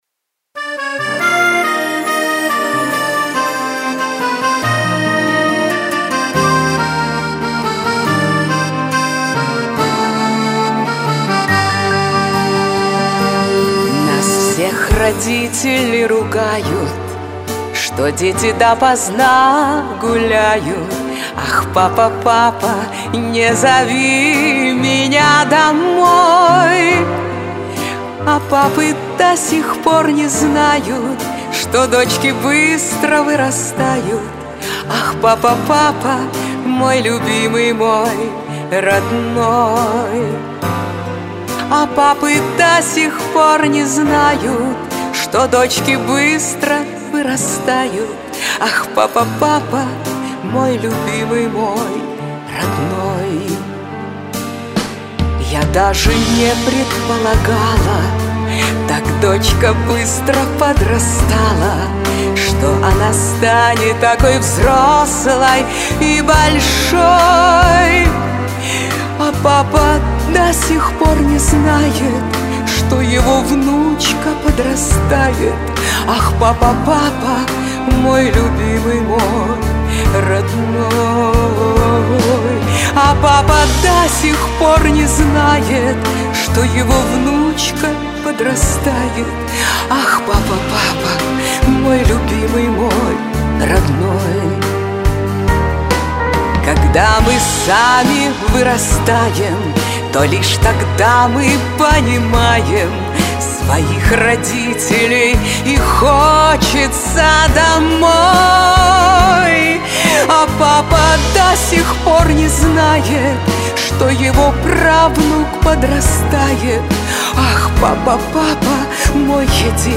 Песня-исповедь